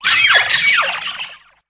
Grito de Wugtrio.ogg
) Categoría:Gritos de Pokémon de la novena generación Categoría:Wugtrio No puedes sobrescribir este archivo.
Grito_de_Wugtrio.ogg